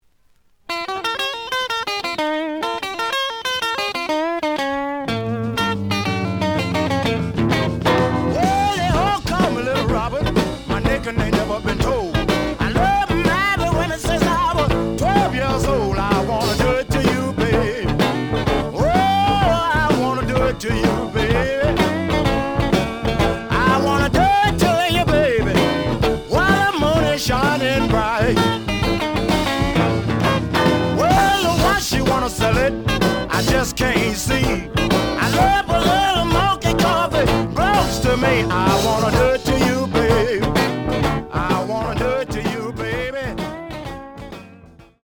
The audio sample is recorded from the actual item.
●Format: 7 inch
●Genre: Blues
Slight edge warp.